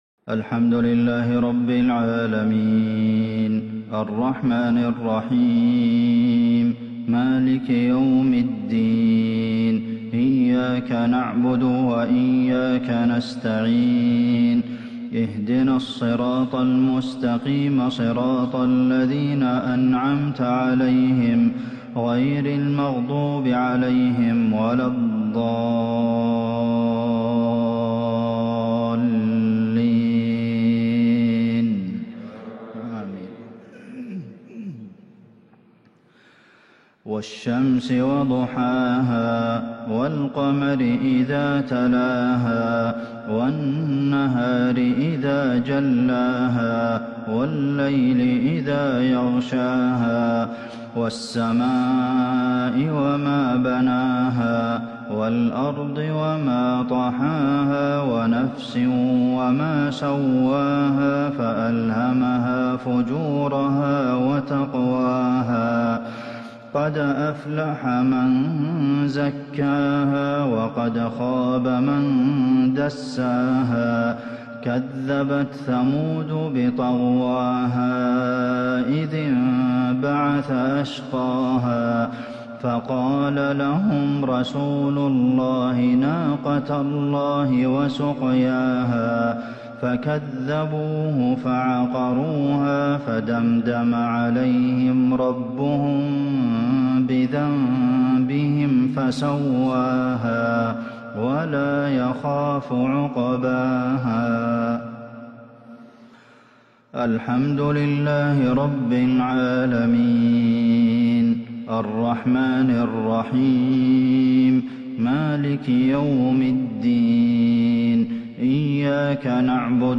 مغرب الخميس 1-6-1442هـ سورتي الشمس والعصر | Maghrib prayer Surah Ash-Shams and Al-‘Asr 14/1/2021 > 1442 🕌 > الفروض - تلاوات الحرمين